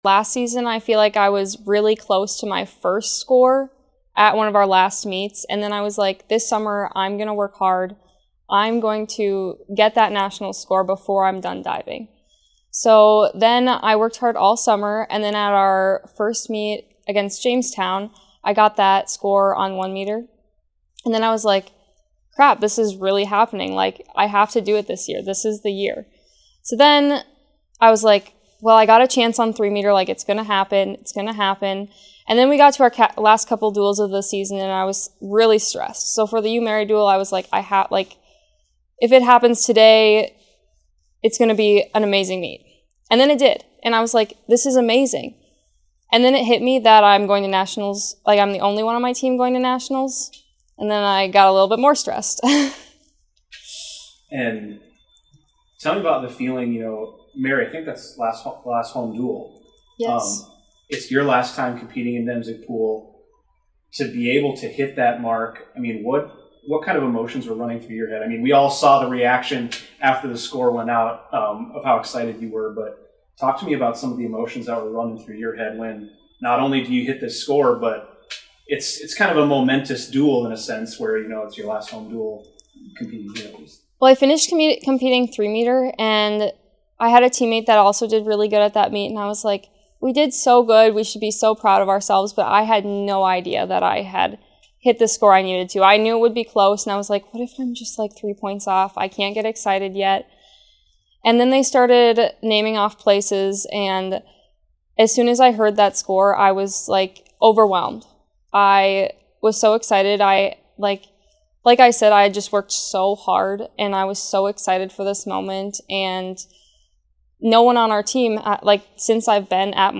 In this interview she breaks down the moment she hit her qualifying score, the coaches and teammates she credits for her growth, and preparing for her biggest stage yet.